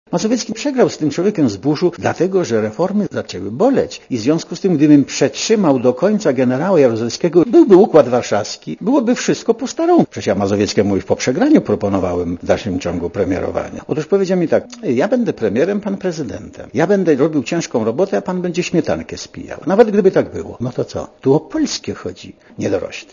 Nie ze mną, a z Tymińskim - dodaje Wałęsa.